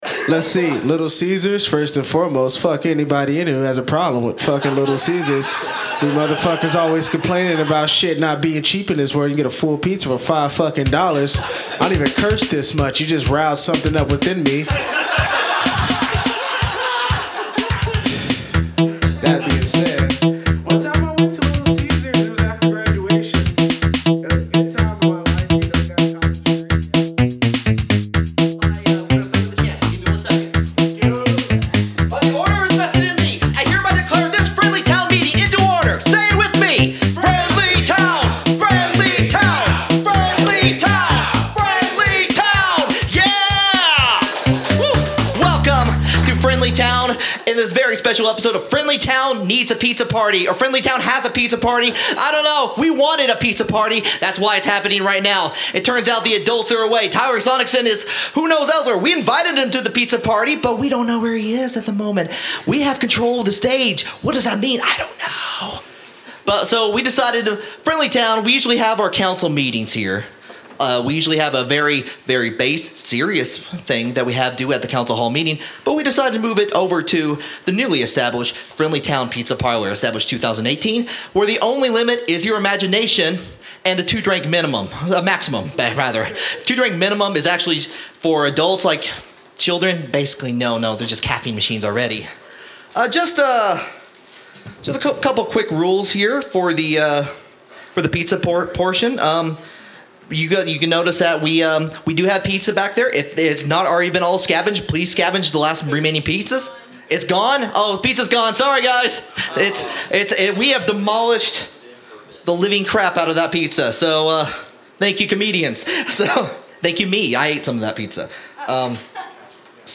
Recorded Live at the Pilot Light on March 12, 2018 Subscribe on iTunes here!